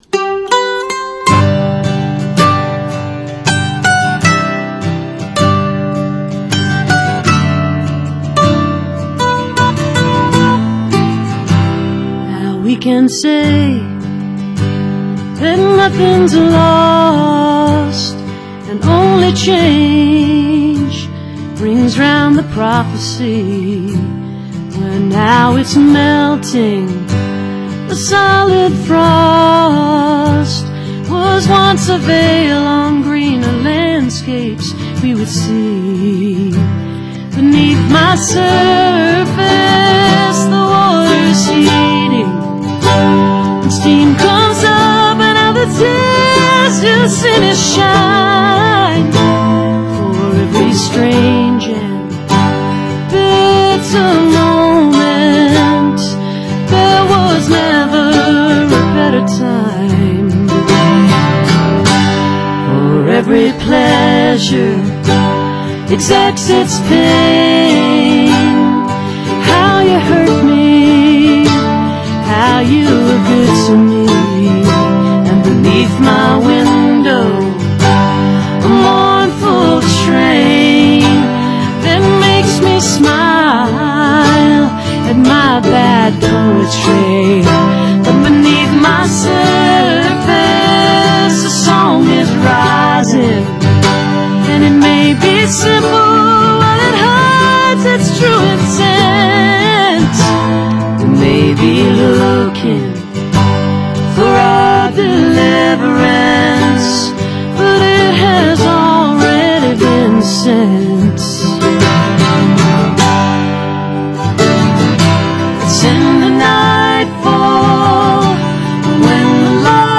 (acoustic duo performance)